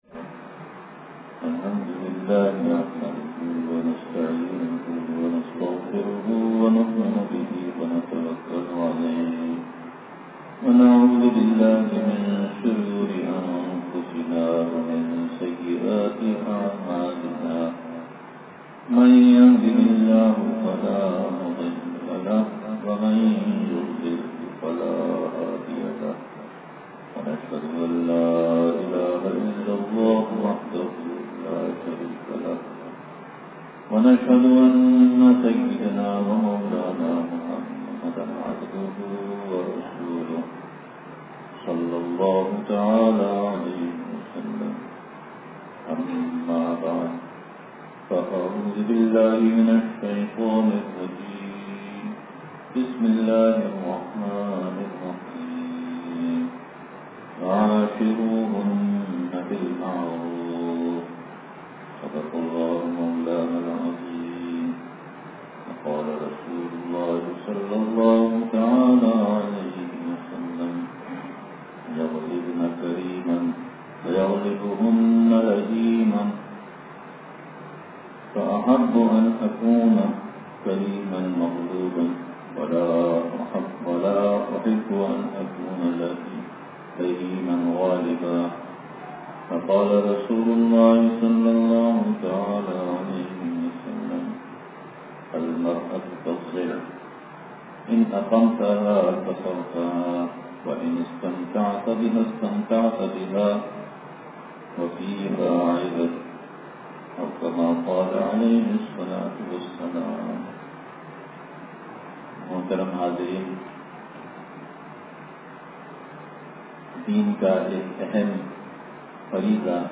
بیان بعد نماز مغرب مدرسہ ابو بکر مرکز امداد و اشرف نزد معمار ہاؤسنگ کراچی